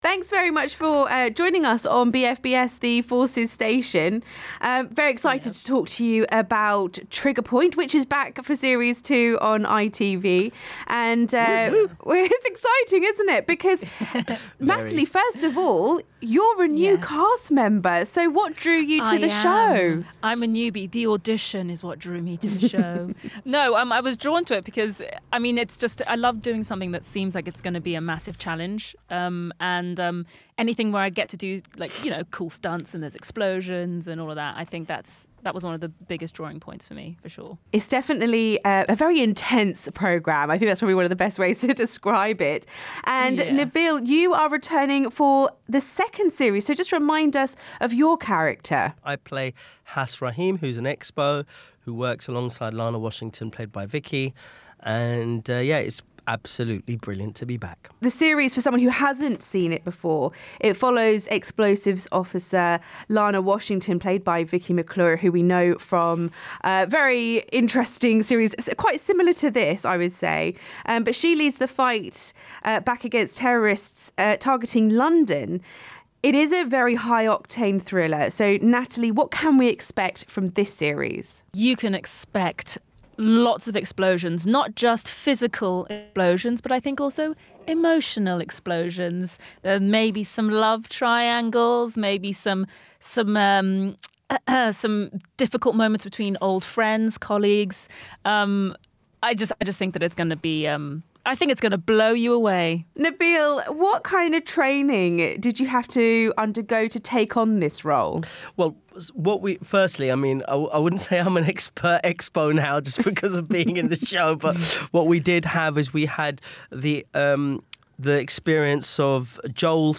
Nabil Elouahabi and Natalie Simpson talk about Trigger Point